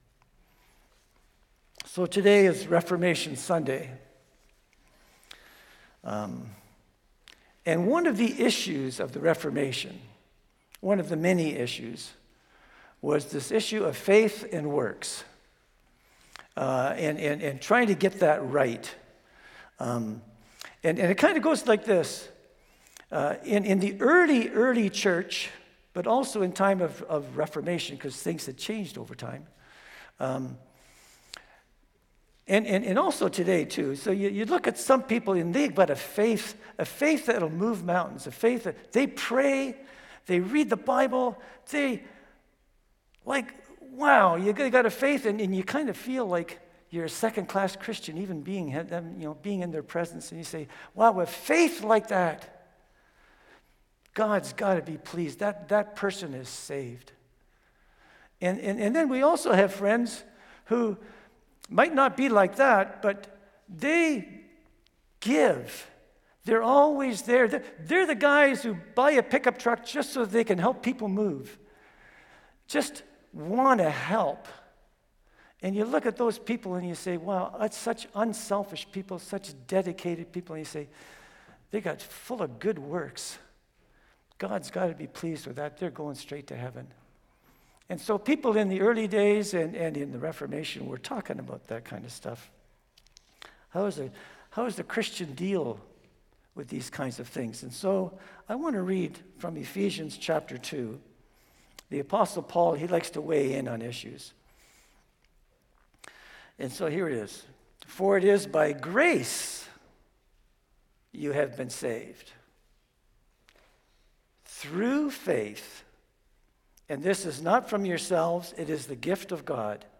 Sermons | Community Christian Reformed Church